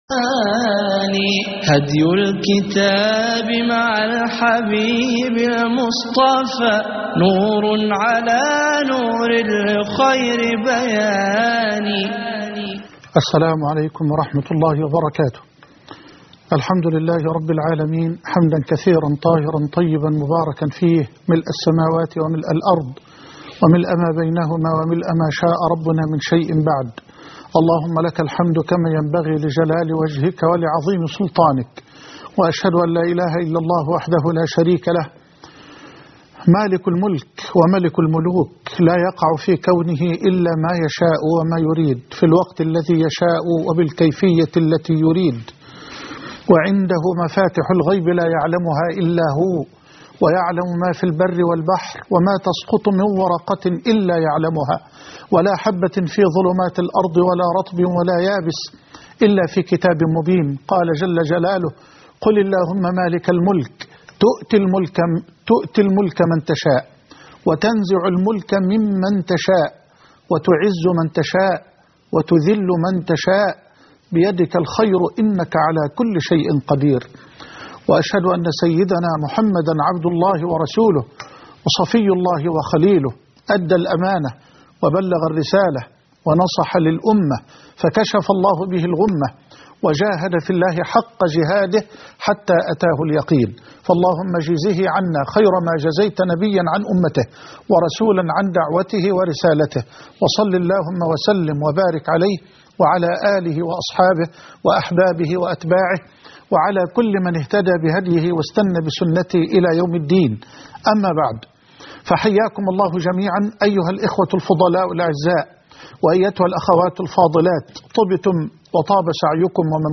نهاية الظالمين (3/6/2012) لقاء خاص - فضيلة الشيخ محمد حسان